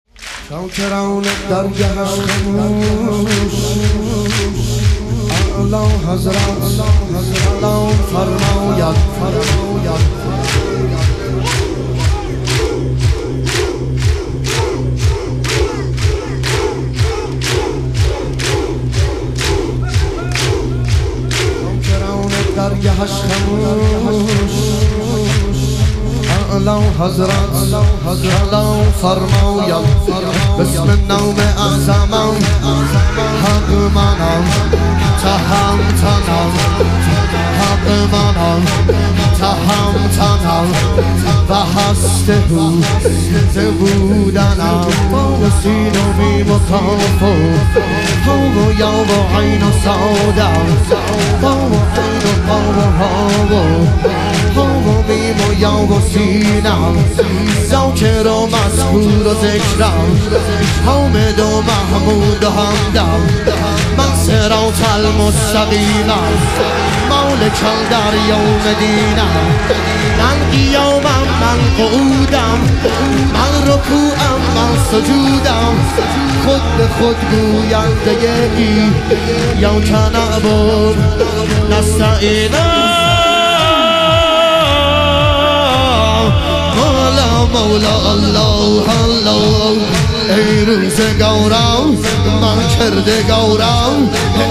شهادت امام صادق علیه السلام - تک